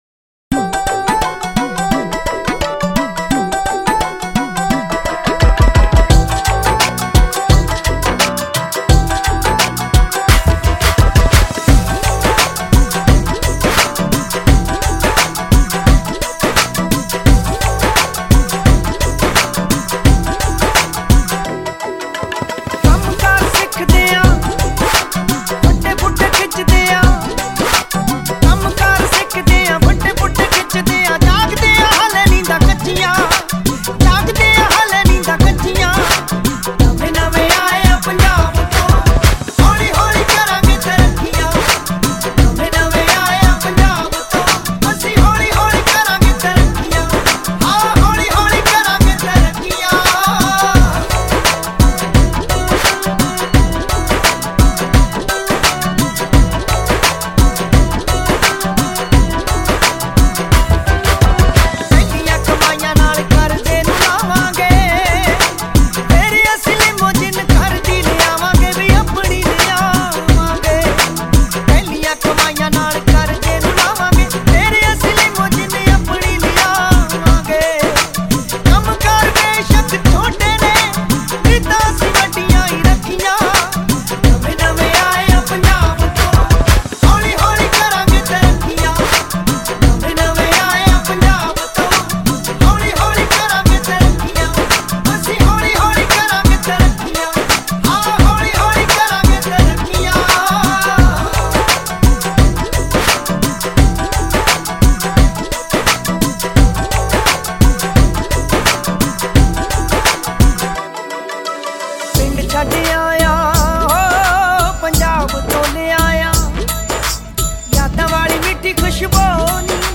Category: UK Punjabi